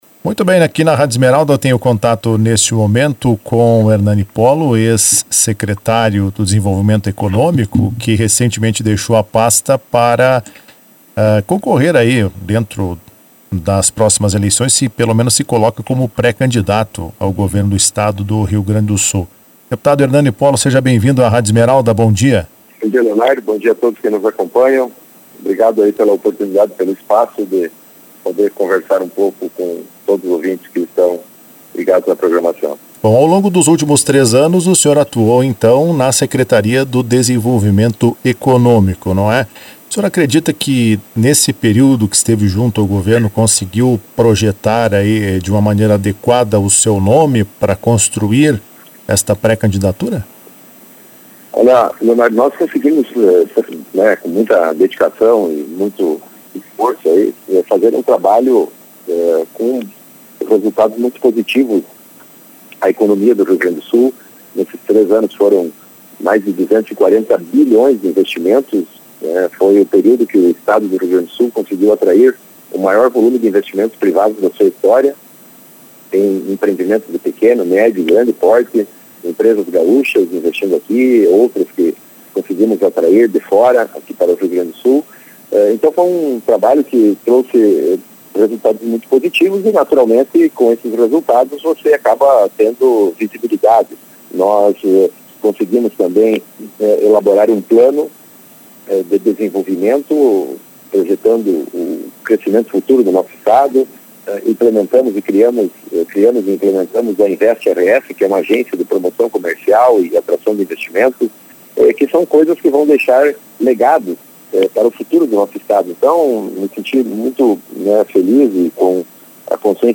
Em entrevista à Rádio Esmeralda nesta sexta-feira, 16, Polo disse que irá iniciar um processo junto com as bases do partido em torno de seu nome.